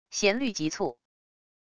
弦律急促wav音频